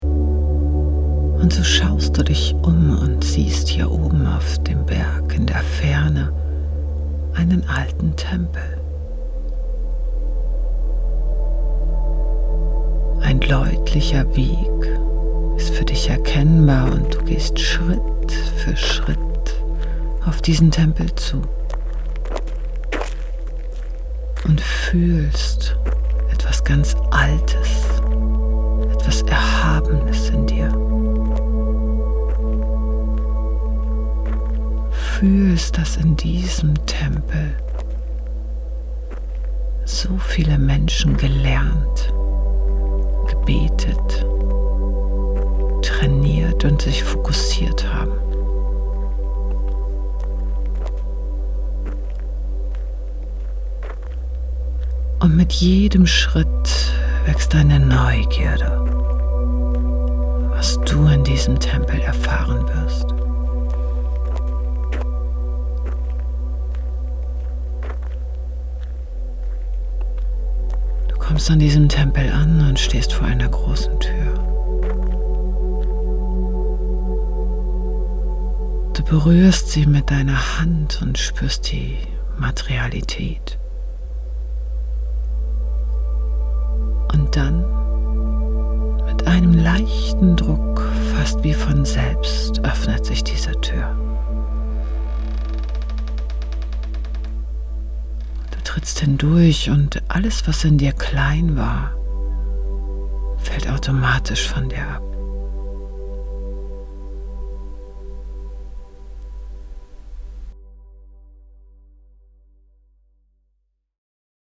Passend zu jeder Rauhnacht eine Meditation aus dem Seelengarten